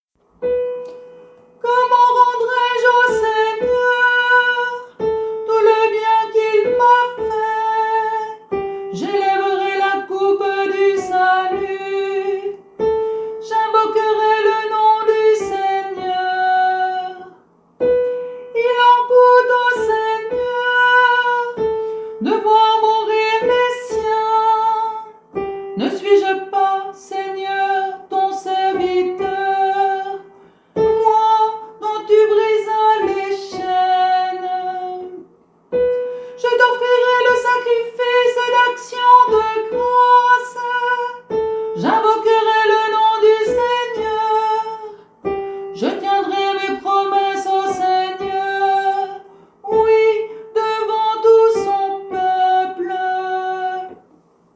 Mélodie